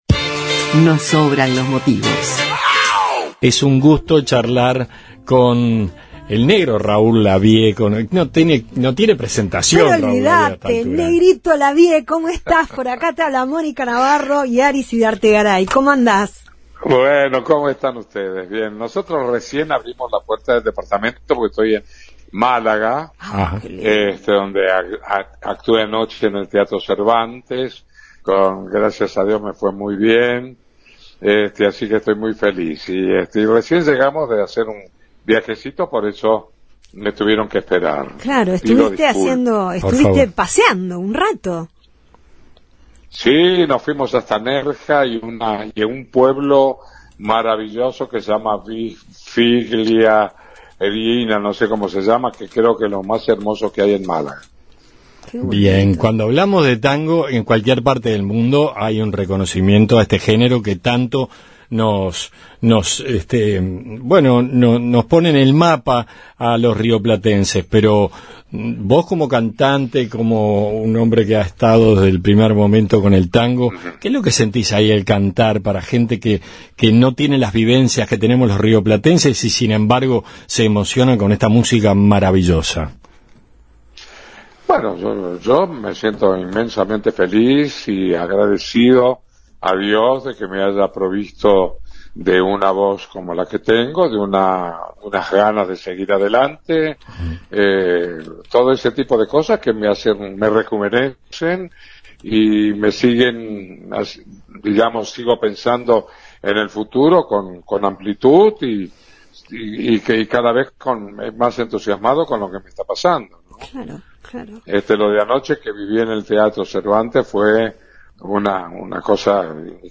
Desde Málaga donde está girando con su espectáculo, hablamos con Raúl Lavie